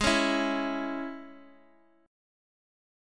Absus4#5 Chord
Listen to Absus4#5 strummed